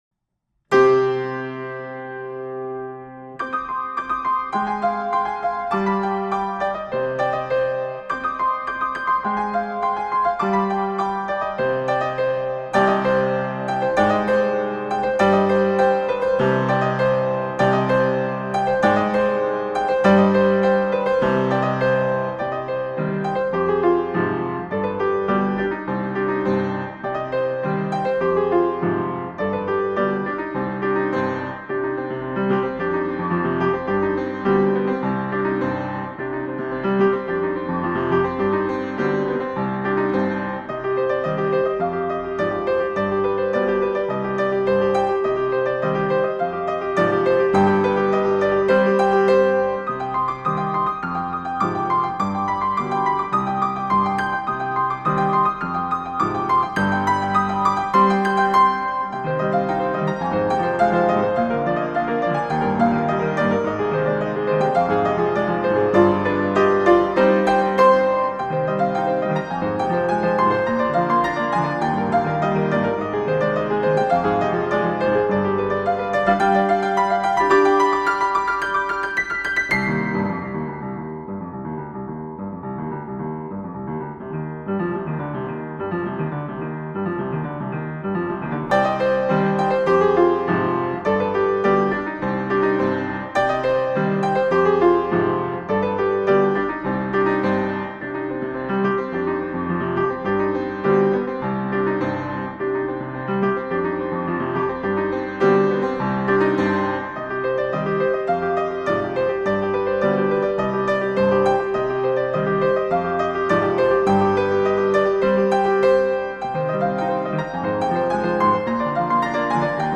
用明快的节奏描述了饱受战火洗礼后的克罗地亚，让人深刻感受到战火的无情，和人们对和平的渴望,以及对未来生活美好的向往